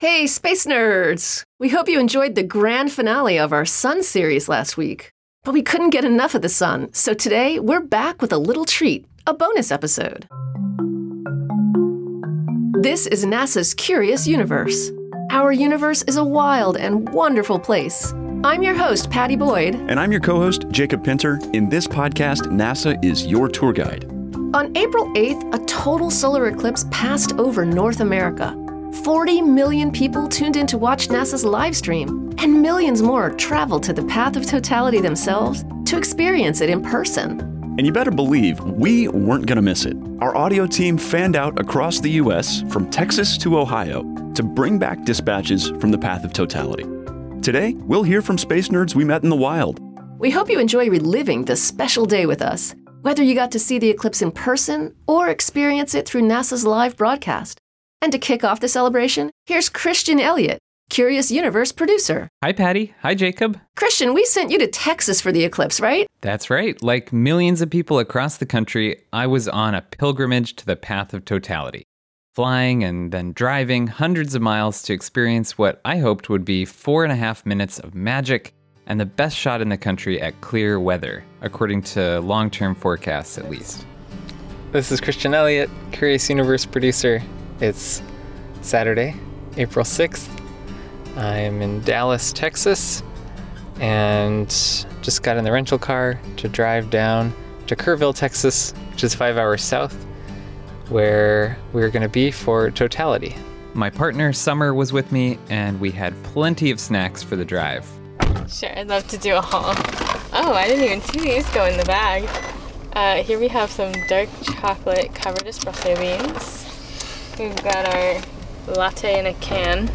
Relive the 2024 total solar eclipse over North America through the eyes and ears of NASA's field reporters and space nerds like you.
On April 8, 2024, North America experienced its last total solar eclipse until the 2040s. As the Moon’s shadow fell across the U.S., NASA sent Curious Universe producers out into the field across the path of totality to talk to space nerds and eclipse scientists.